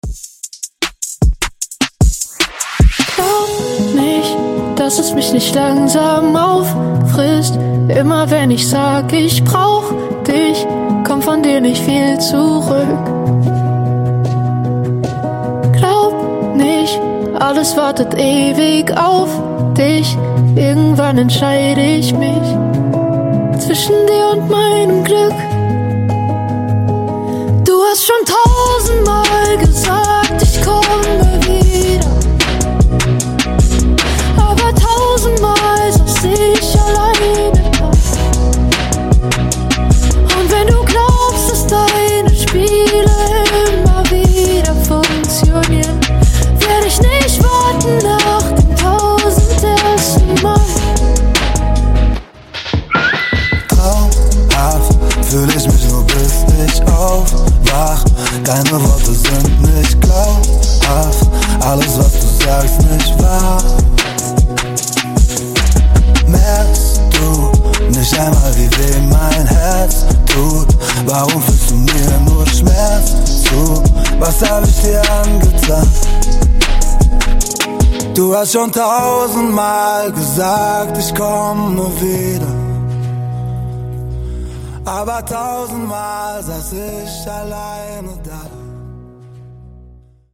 Genre: DANCE
Clean BPM: 128 Time